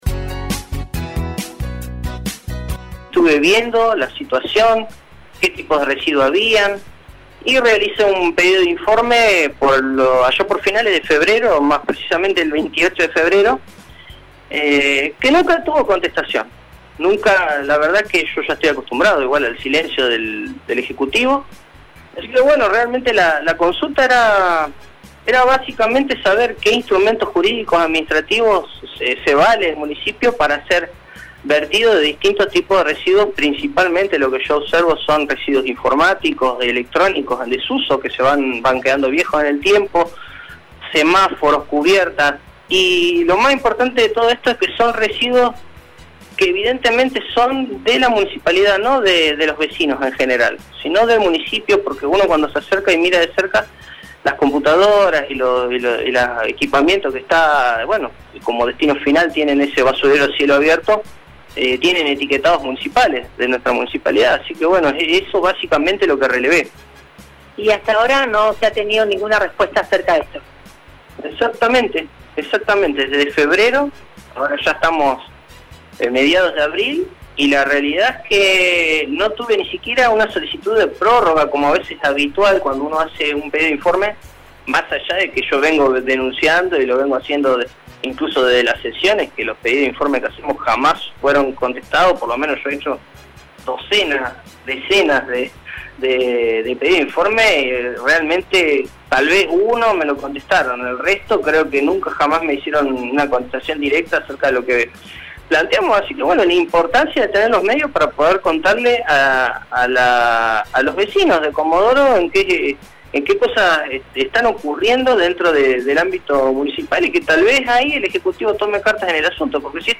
Así lo manifestaba el concejal a los micrófonos de RADIOVISIÓN